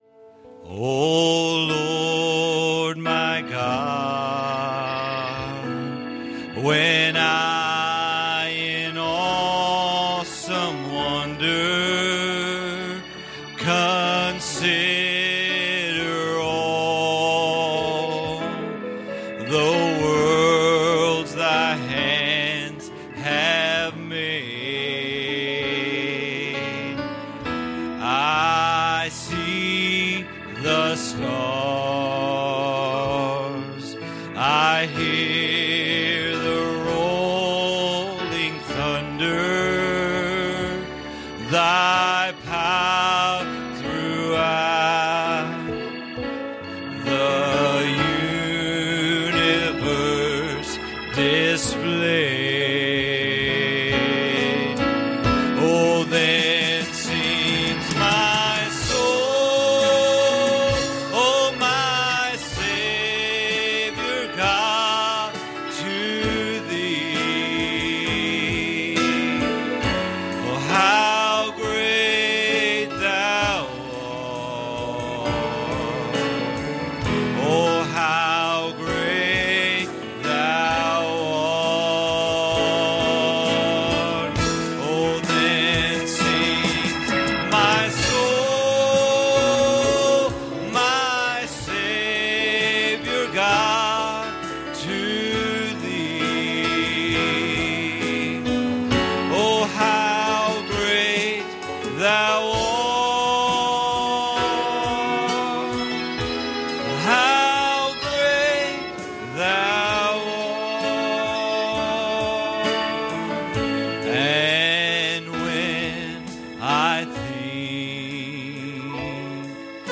Passage: 1 Corinthians 13:1-3 Service Type: Sunday Morning